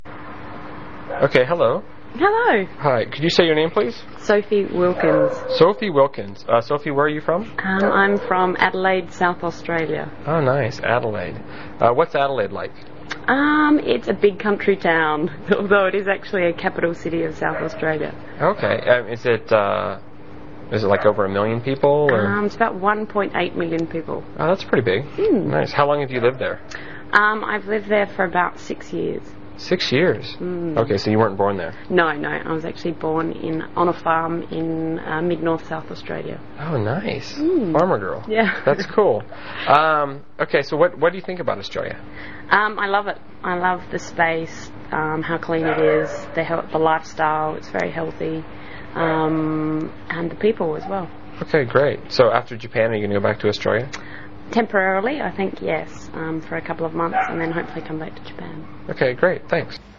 英语高级口语对话正常语速15:阿德莱德（MP3）